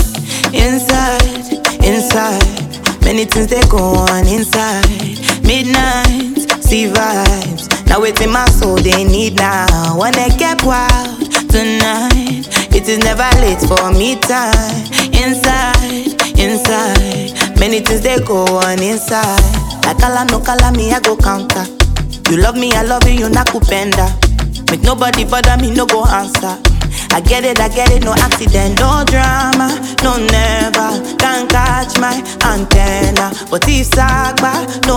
Жанр: Поп / Африканская музыка